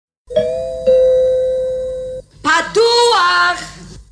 Krovim Doorbell